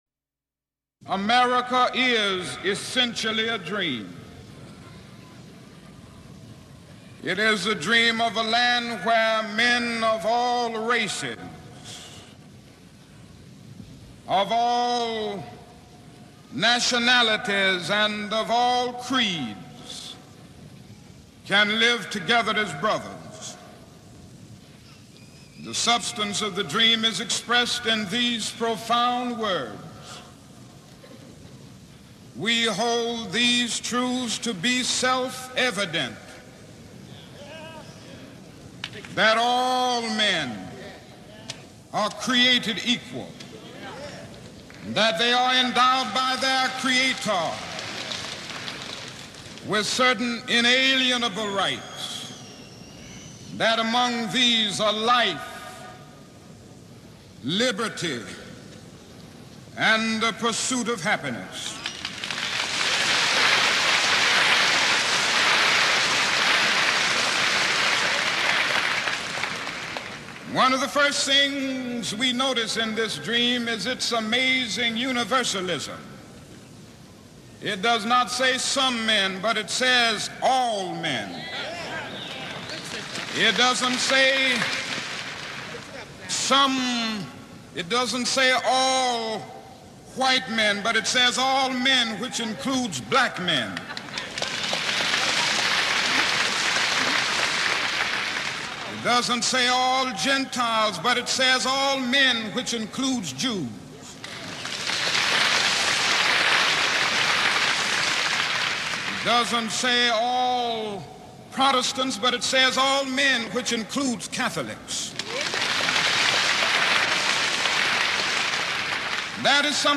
But better than a good speaker, Dr King was a great speaker… easily as able to captivate his listeners today, as he was many years ago.
The only thing jarring to my ears is the now rarely heard word “Negro”, which Dr King used, to refer to people of colour.
Below, is a segment of Dr. King’s “American Dream” speech.
The American Dream Speech